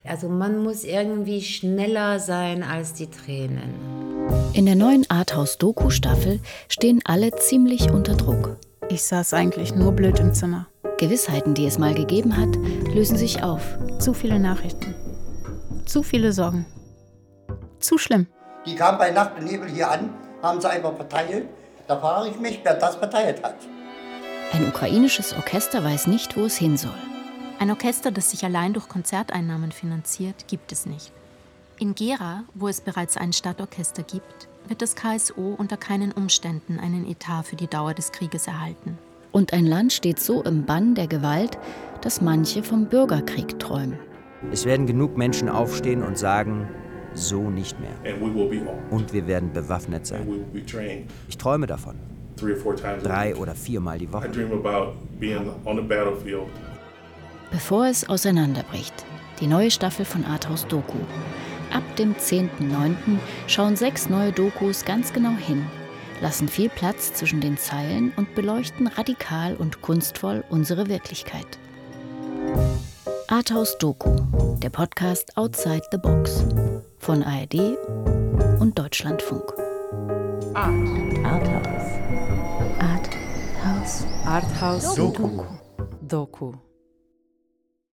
In den aufwendig produzierten Audiodokus hören wir das Murmeln eines unerklärten Bürgerkriegs in den USA.
Wir lauschen dem Kyiver Sinfonieorchester, das kurz vor seiner zweiten Heimatlosigkeit steht. Wir vernehmen das Piepsen der Geräte im Krankenhaus während lebensrettender Operationen.